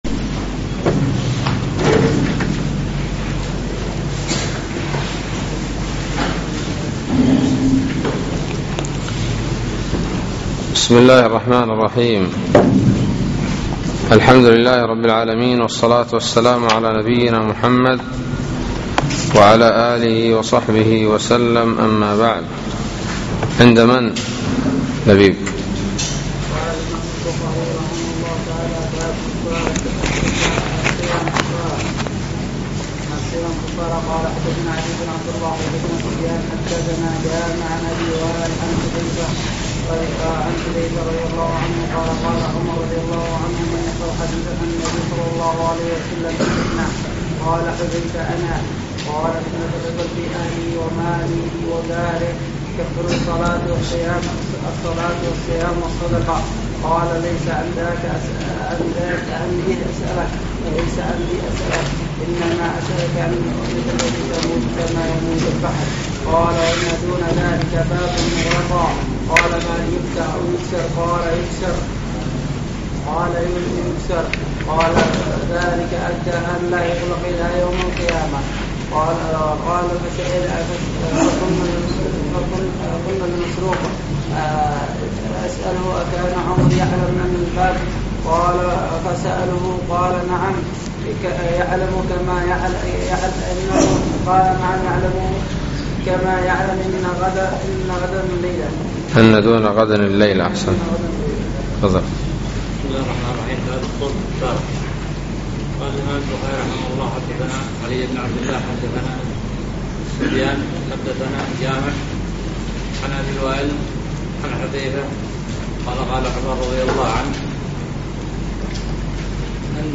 الدرس الرابع : باب الريان للصائمين